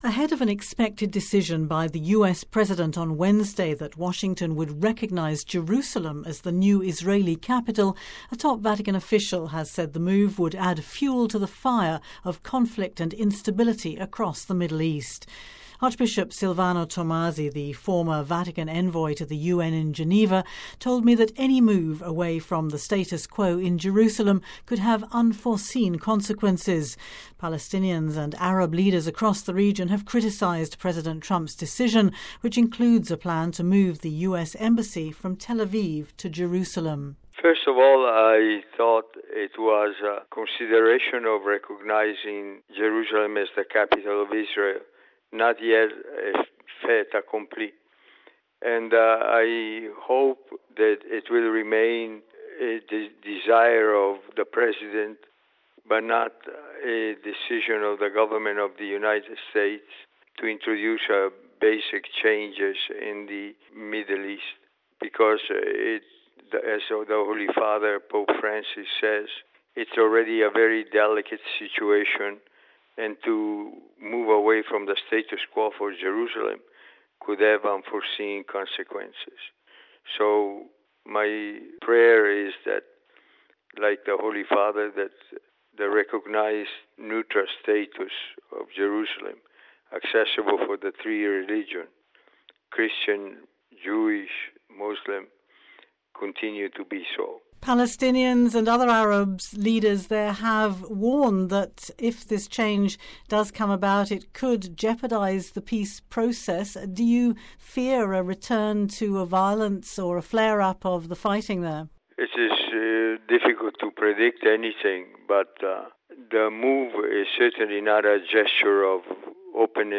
Listen to Archbishop Silvano Tomasi speaking